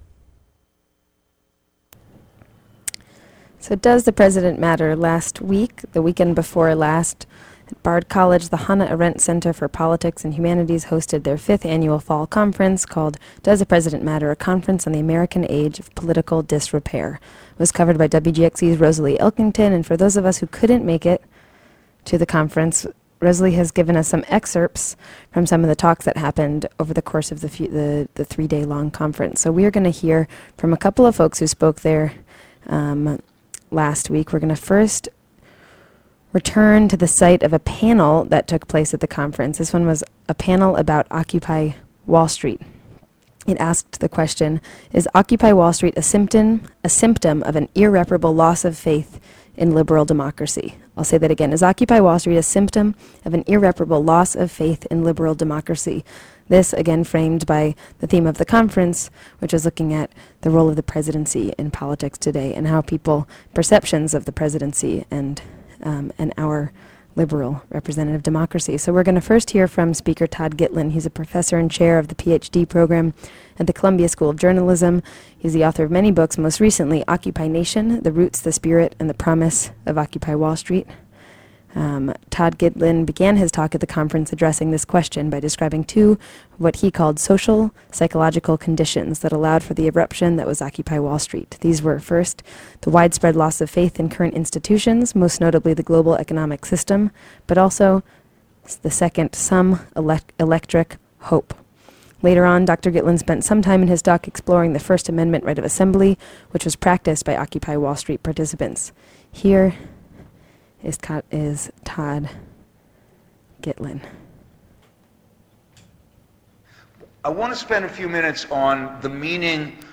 and excerpts from Ralph Nader's speech.